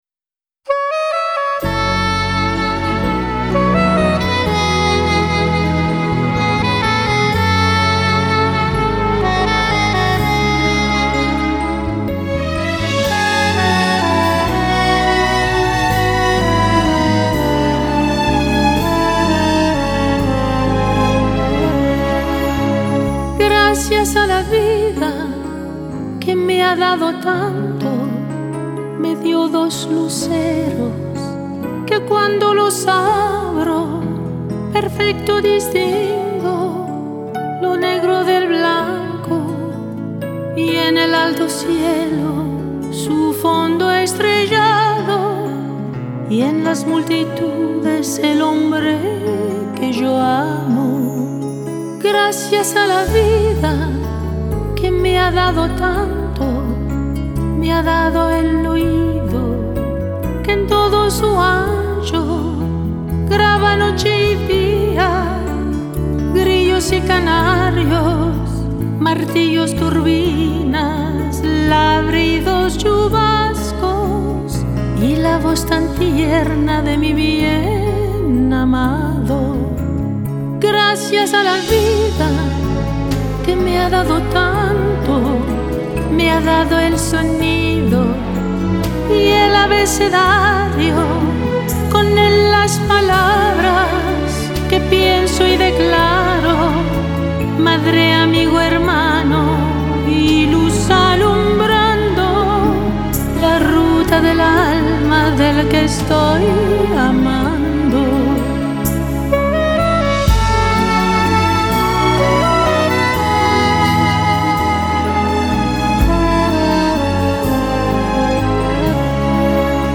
Genre: Latin Pop